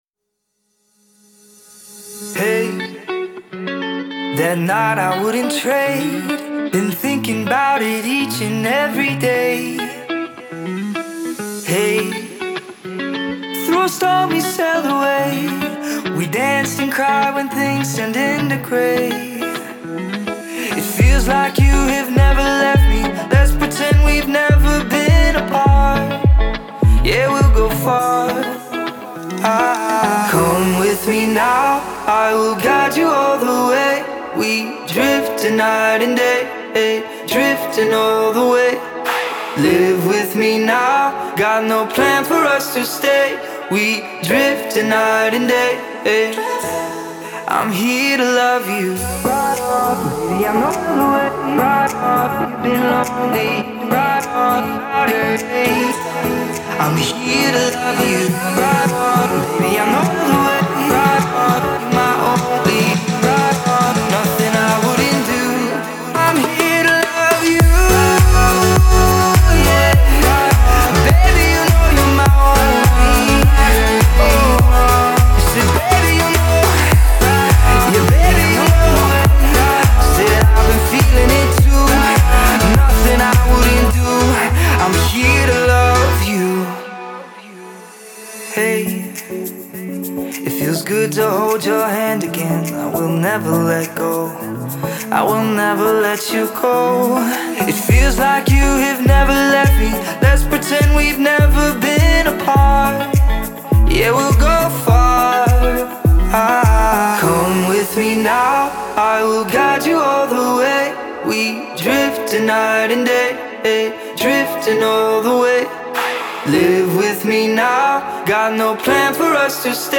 энергичная трек в жанре электронной музыки